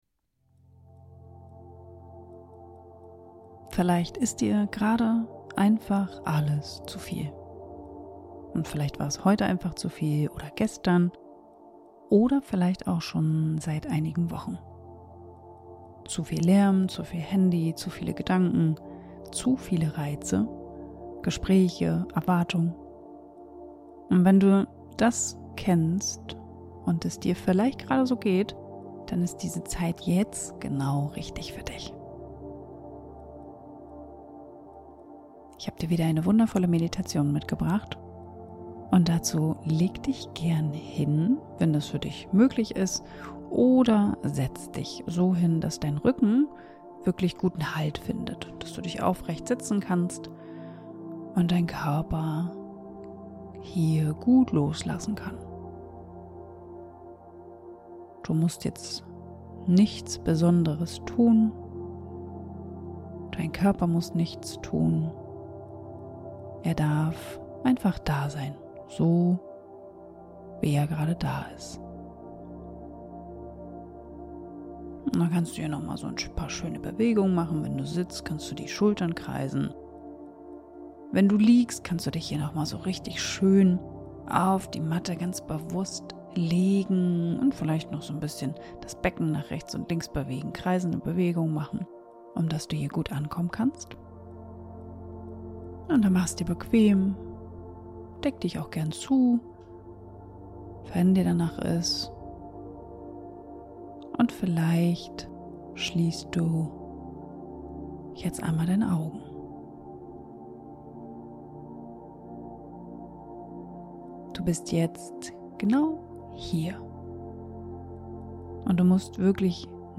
Genau dafür ist diese Meditation.
Wir arbeiten mit deinem Atem, mit deinem Körper, mit dem, was gerade da ist. Ich führe dich sanft durch eine Reise, die dich wieder bei dir ankommen lässt.